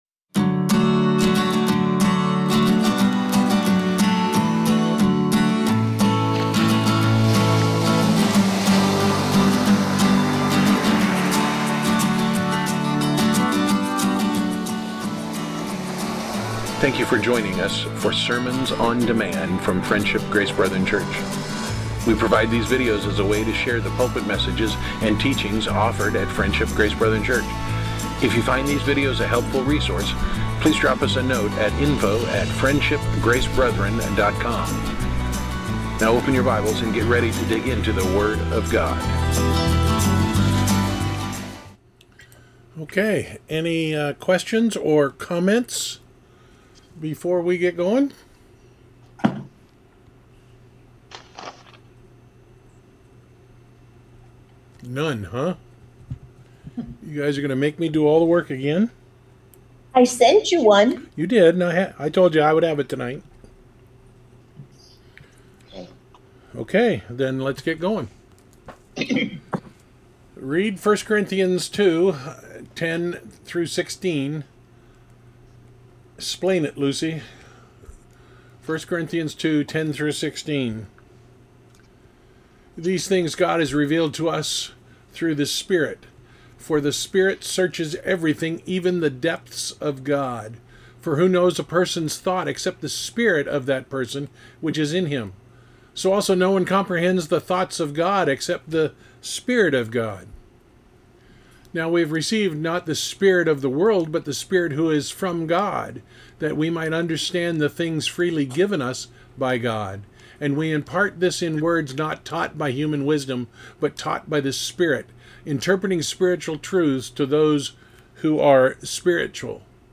Series: Weekly Bible Discussion